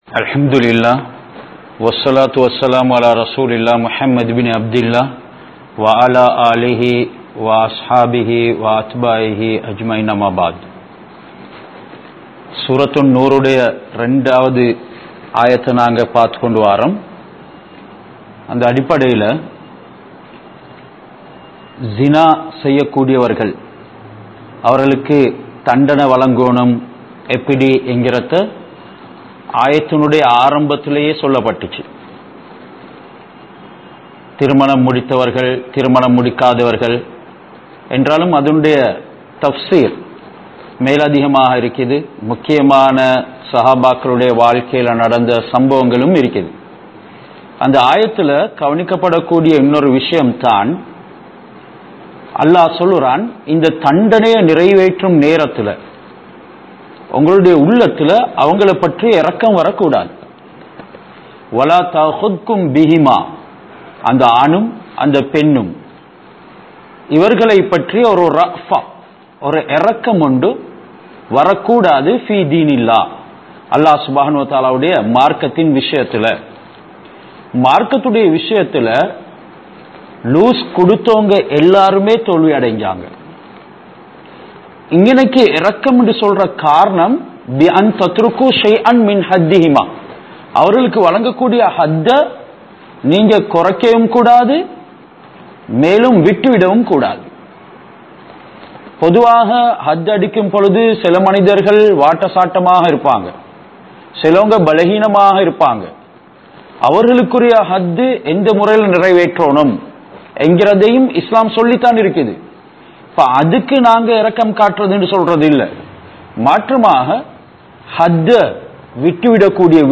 Suah An Noor(Thafseer Verses 2-3) | Audio Bayans | All Ceylon Muslim Youth Community | Addalaichenai